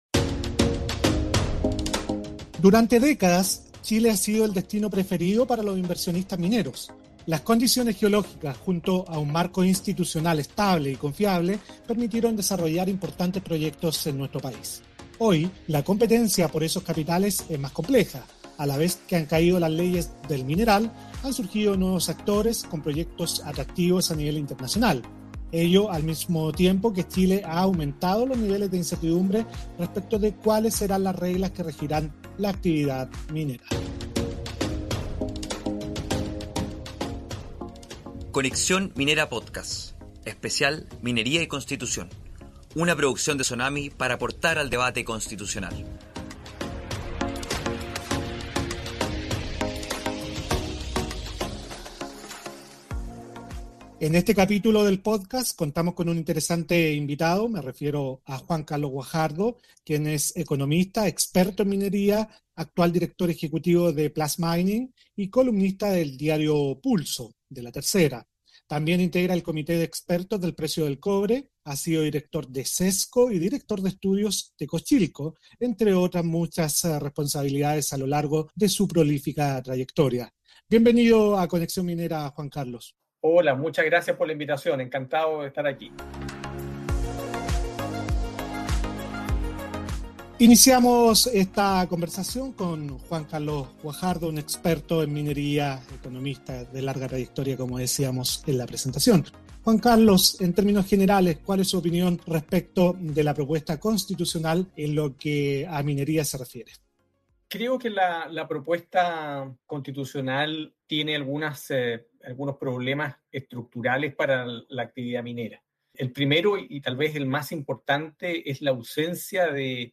Como parte del debate constitucional, SONAMI ha comenzado una serie de podcasts en que se entrevistan a diversos personeros del ámbito minero y otros sectores para analizar el borrador y la futura nueva Constitución, que será sometida a plebiscito el 4 de septiembre.